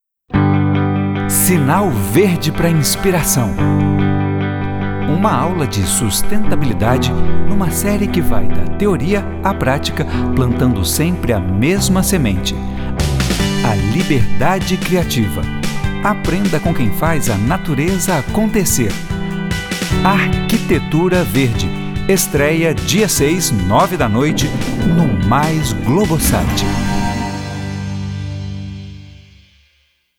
Masculino
Voz Jovem 00:30
• Tenho voz leve e versátil, e interpretação mais despojada.